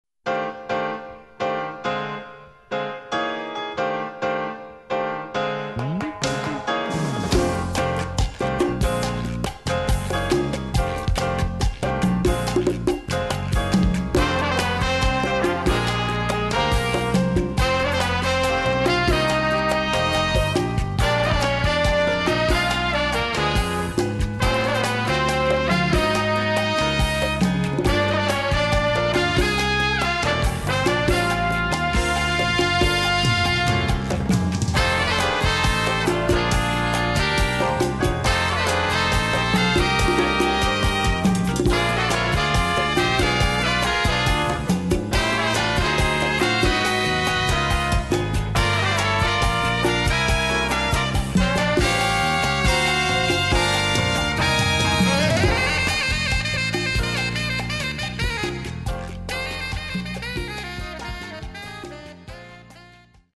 Category: Little Big Band
Style: Cha Cha
Solos: tenor sax/trombone/piano/percussion.